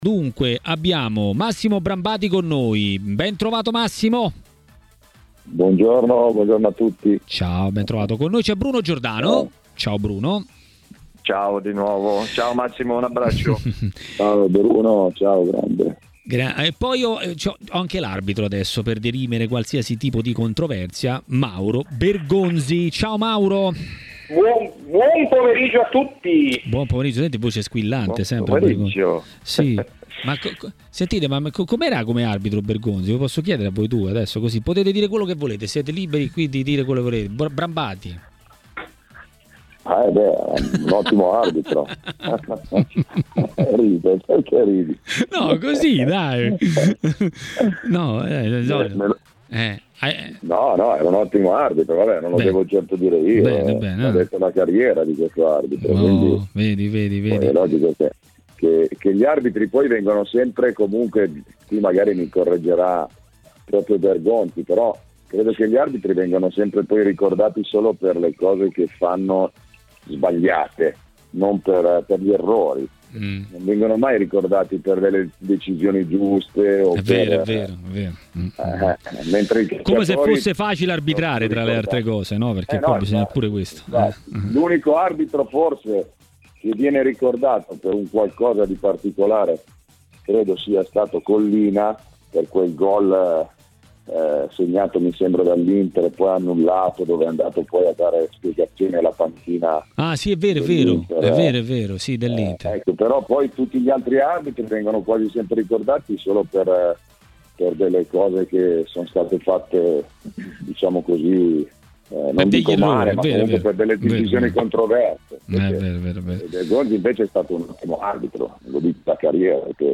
ha detto la sua sui temi di giornata a Maracanà, nel pomeriggio di TMW Radio.